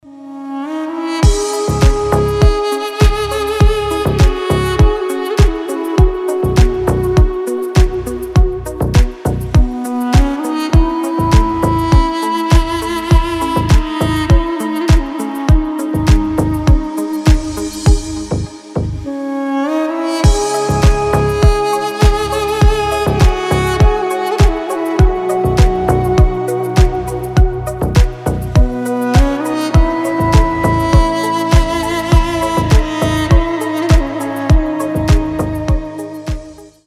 • Качество: 320, Stereo
красивые
deep house
спокойные
дудук